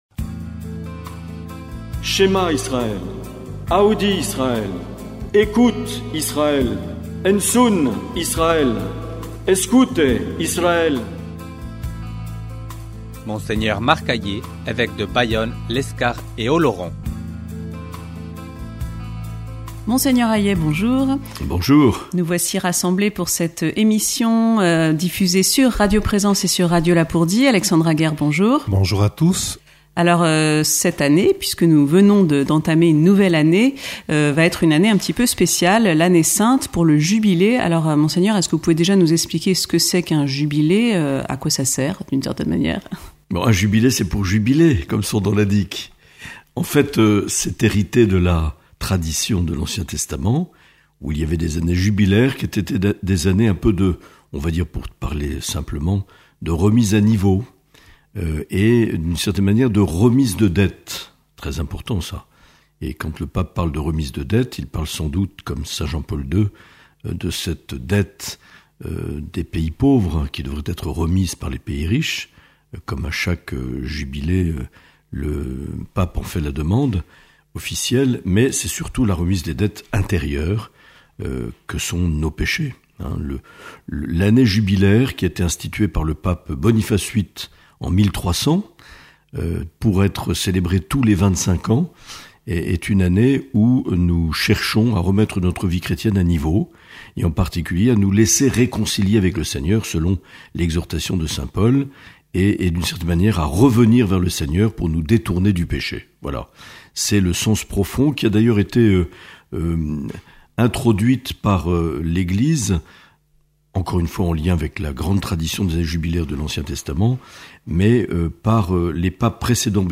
Dans cet entretien enregistré le 3 décembre 2024, Mgr Marc Aillet répond aux questions de Radio Lapurdi et de Radio Présence Lourdes Pyrénées.